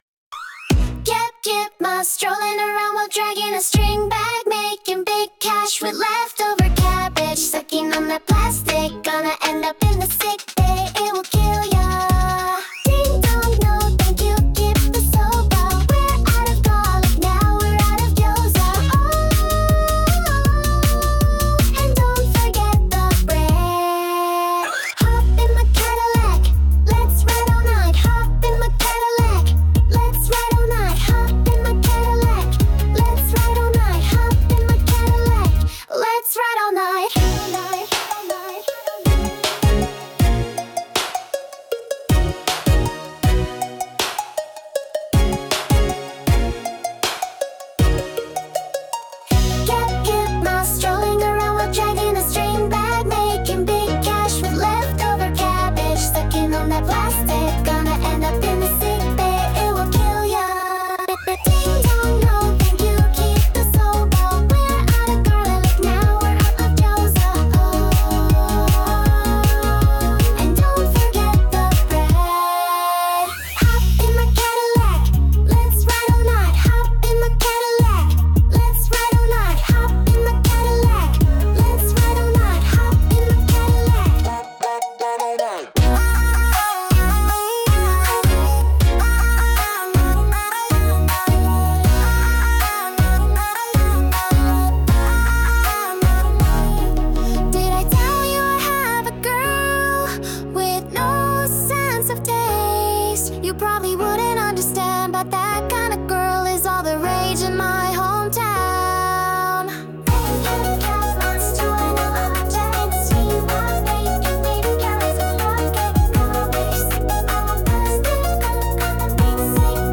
• ジャンル：エラーポップアイドル
• 声：明るくハスキー／喋りが高速気味